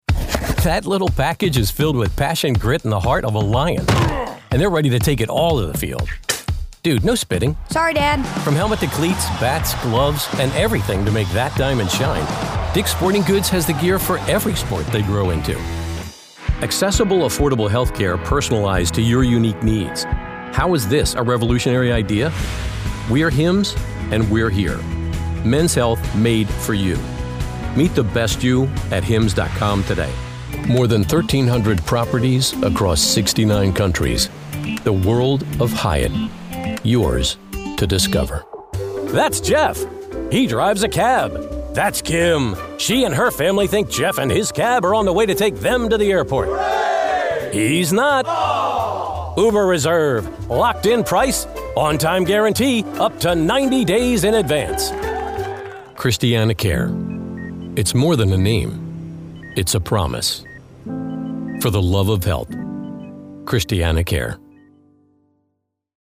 Commercial
• Sennheiser MKH416
• Professional Recording Booth
• Authentic, Approachable, Conversational and Friendly